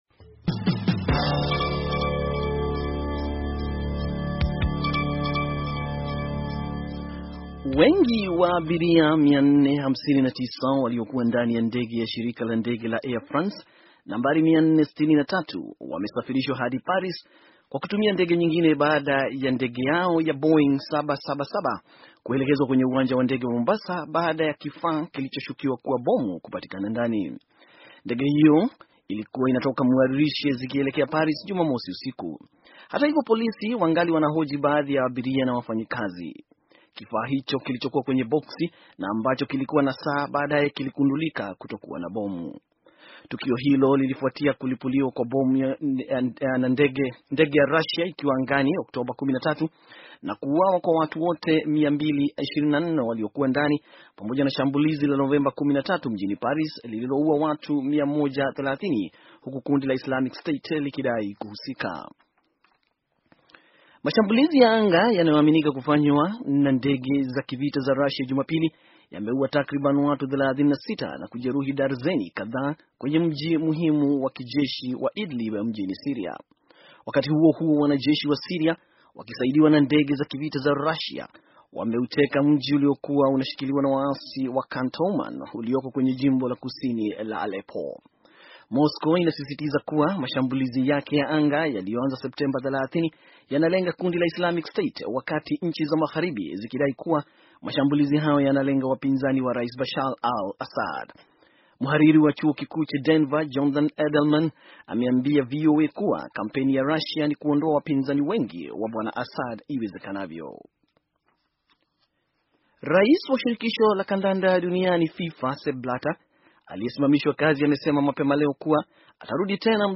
Taarifa ya habari - 5:16